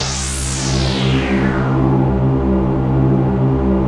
ATMOPAD23.wav